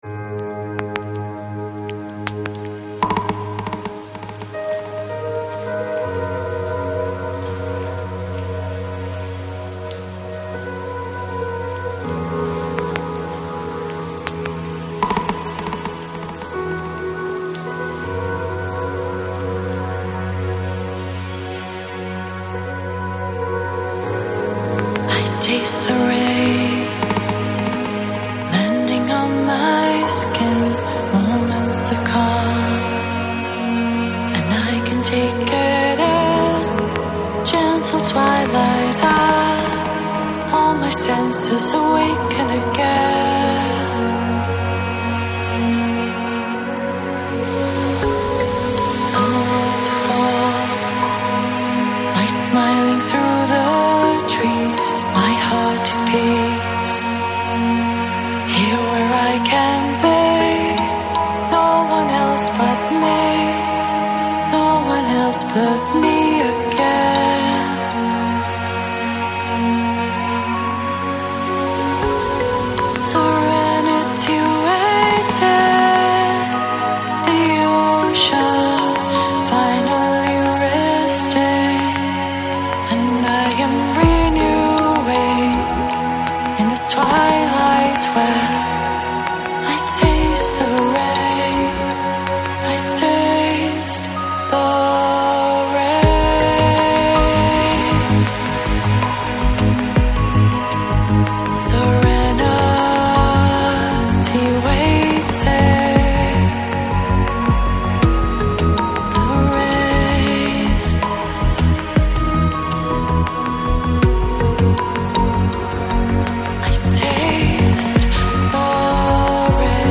Жанр: Balearic, Downtempo